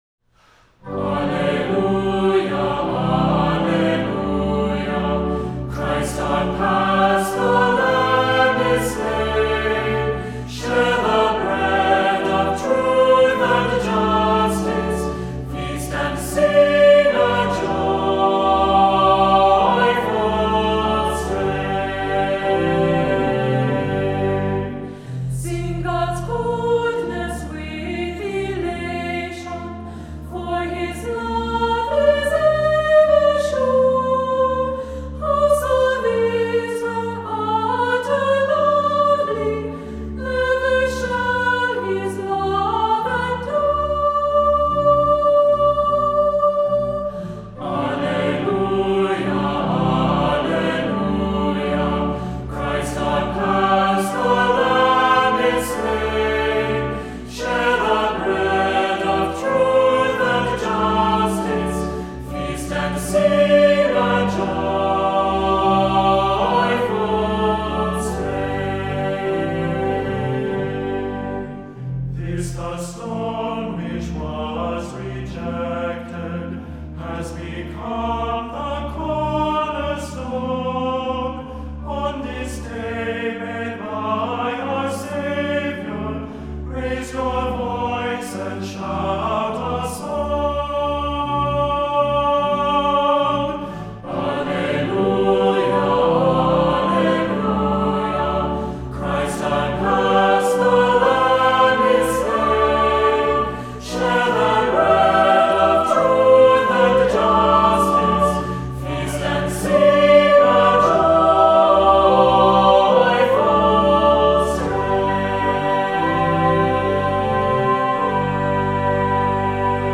Voicing: Unison Choir,Cantor,Assembly,Descant